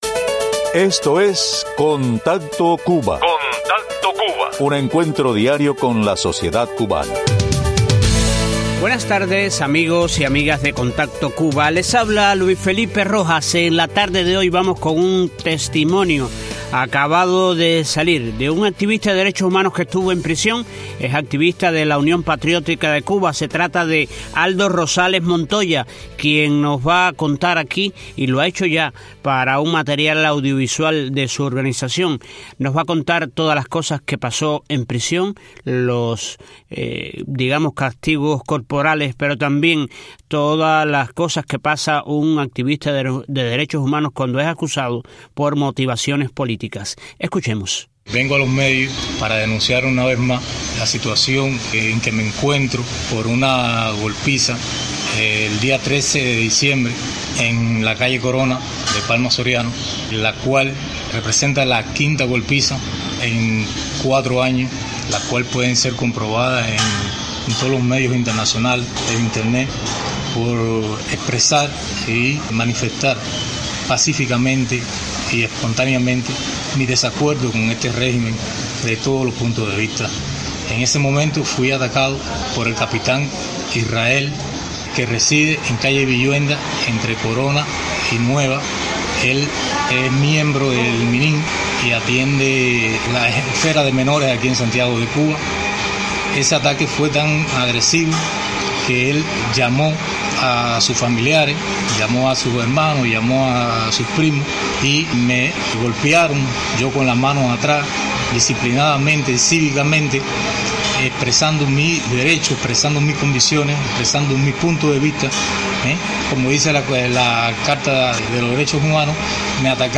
Testimonio de un ex preso político cubano